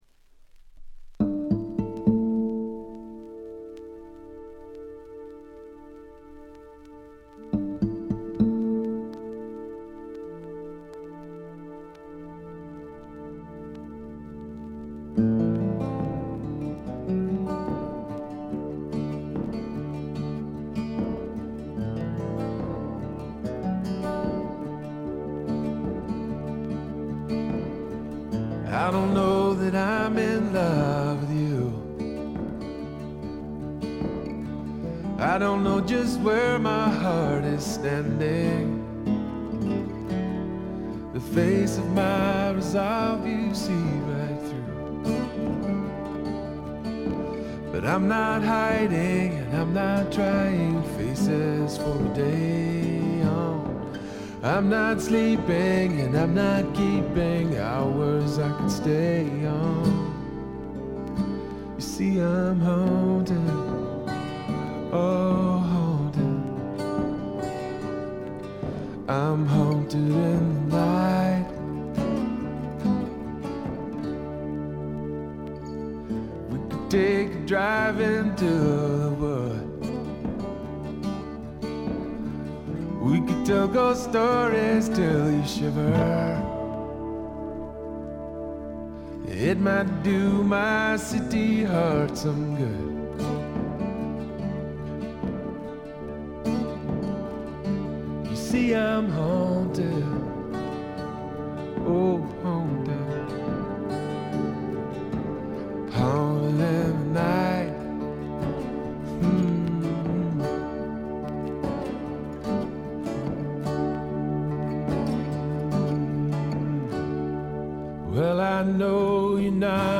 静音部で軽微なチリプチ。
メロウ系、AOR系シンガー・ソングライターのずばり名作！
試聴曲は現品からの取り込み音源です。